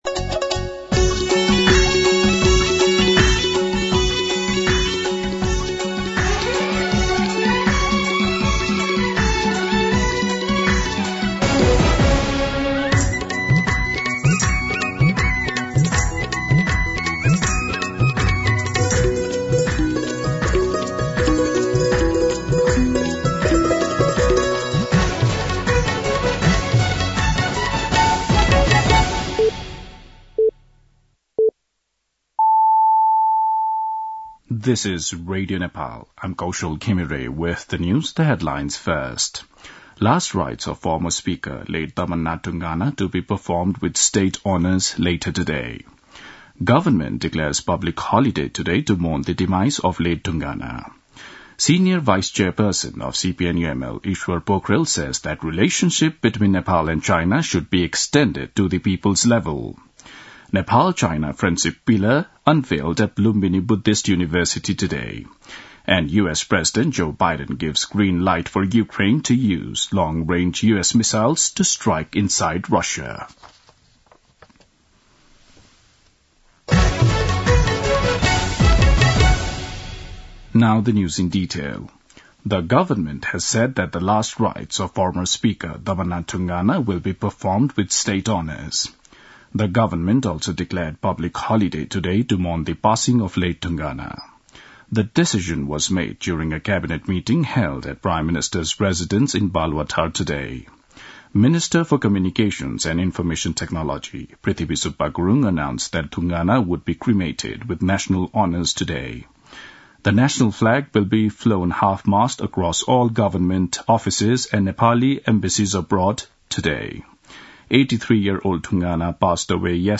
दिउँसो २ बजेको अङ्ग्रेजी समाचार : ४ मंसिर , २०८१
2-pm-English-News-.mp3